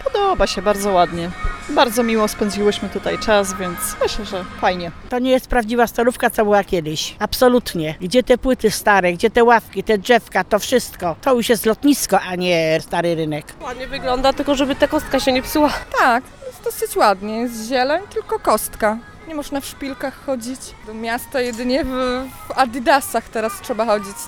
2014-mieszkancy-rynek.mp3